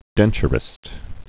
(dĕnchər-ĭst)